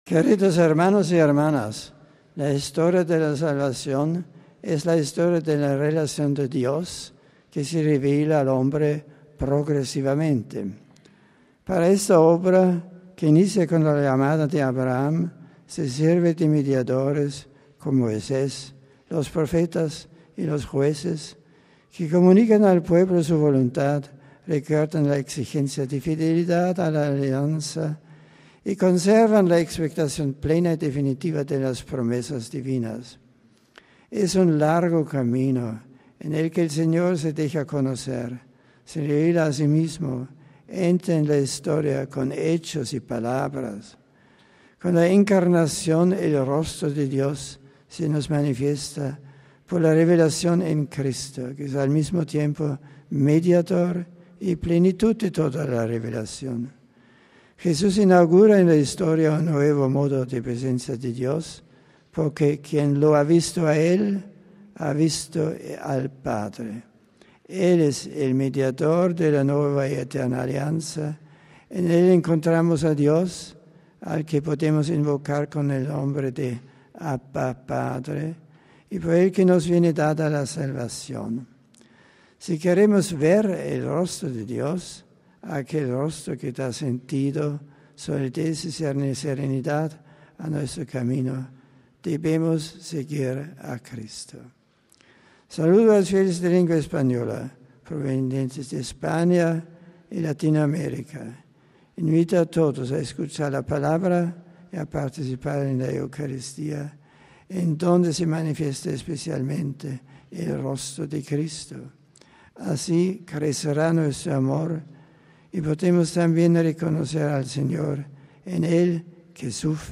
(RV).- (Con audio) En su catequesis de la audiencia general de esta mañana, celebrada en el Aula Pablo VI del Vaticano, ante la presencia de varios miles de fieles y peregrinos de numerosos países, el Papa comenzó recordando que el Concilio Vaticano II, en su Constitución dogmática sobre la divina Revelación, Dei Verbum, afirma que la íntima verdad de toda la Revelación de Dios resplandece para nosotros «en Cristo, que es al mismo tiempo el mediador y la plenitud de toda la Revelación» (n. 2).
En su resumen de esta catequesis en nuestro idioma, el Papa dijo: